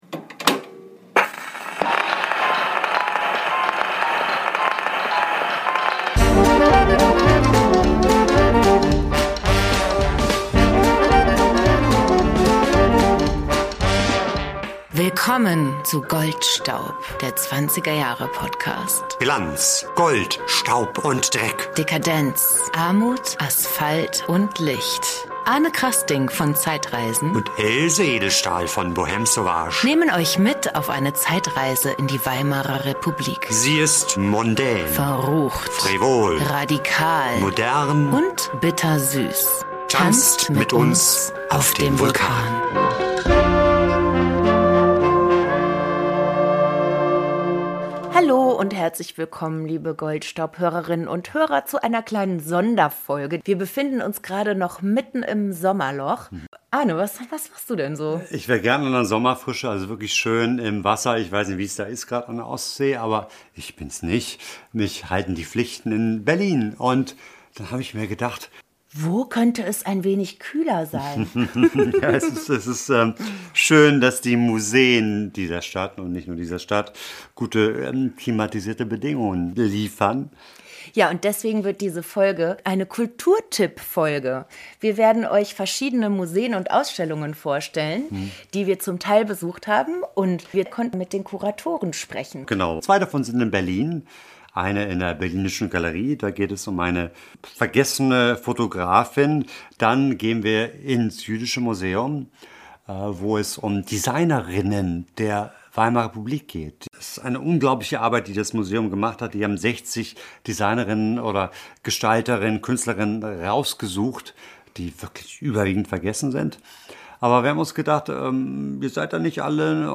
Beschreibung vor 7 Monaten Goldstaub war für euch unterwegs in Berlin, Frankfurt und Mannheim. Wir stellen vier Ausstellungen vor, die sich mit unterschiedlichen Aspekten der 1920er Jahre beschäftigen und durften dafür mit den Kuratorinnen sprechen.